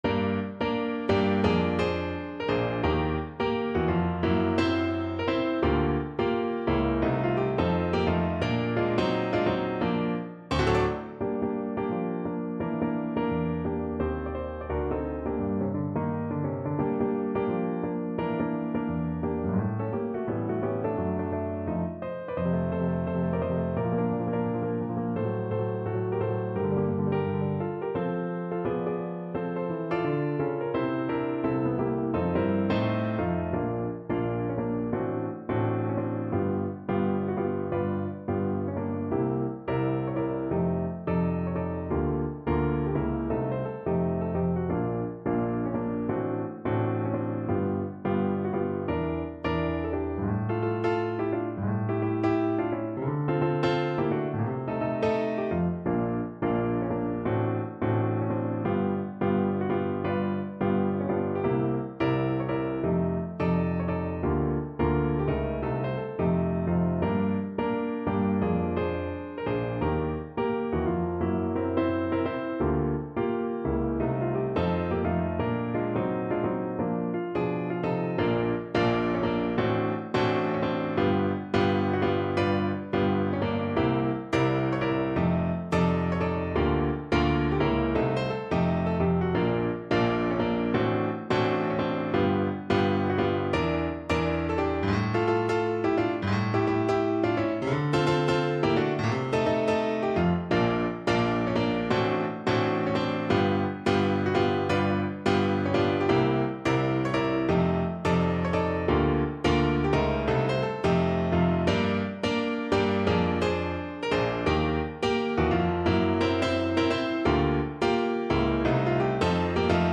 Animato =86
Jazz (View more Jazz Voice Music)